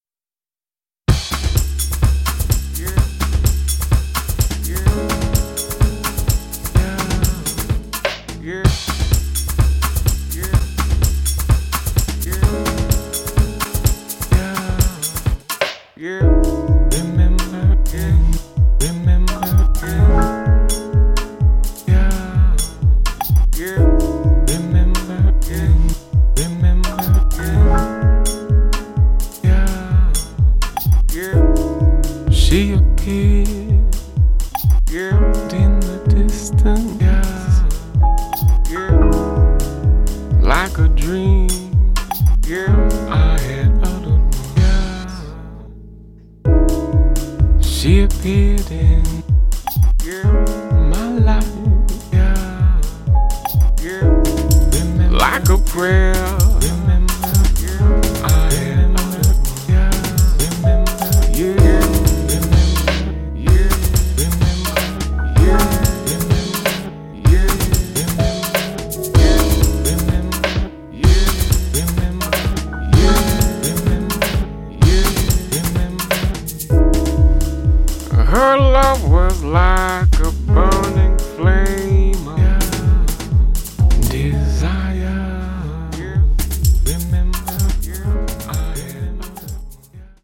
軽快なボッサ調ビートやジャジーなピアノ